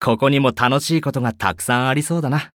文件 文件历史 文件用途 全域文件用途 Ja_Bhan_amb_03.ogg （Ogg Vorbis声音文件，长度2.5秒，111 kbps，文件大小：35 KB） 源地址:游戏语音 文件历史 点击某个日期/时间查看对应时刻的文件。 日期/时间 缩略图 大小 用户 备注 当前 2018年5月25日 (五) 02:58 2.5秒 （35 KB） 地下城与勇士  （ 留言 | 贡献 ） 分类:巴恩·巴休特 分类:地下城与勇士 源地址:游戏语音 您不可以覆盖此文件。